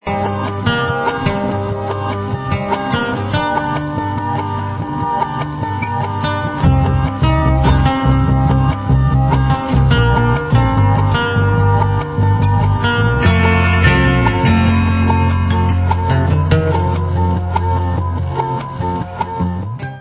sledovat novinky v oddělení Experimentální hudba